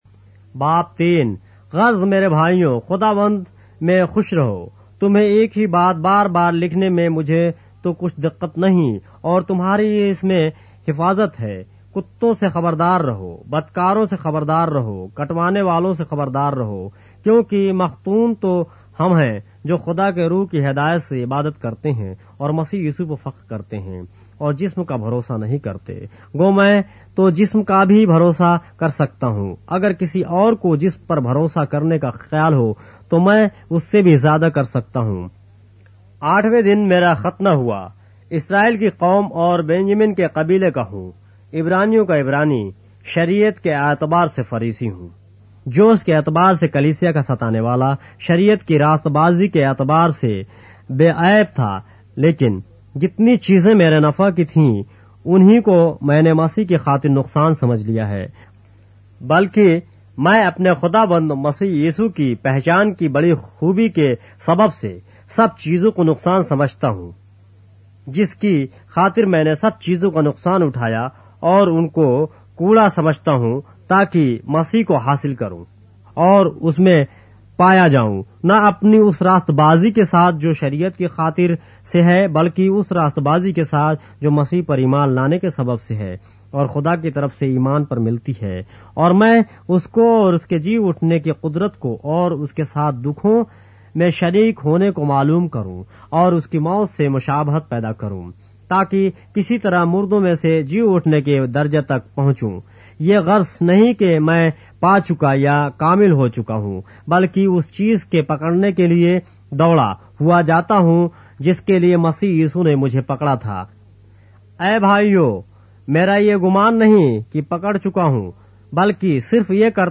اردو بائبل کے باب - آڈیو روایت کے ساتھ - Philippians, chapter 3 of the Holy Bible in Urdu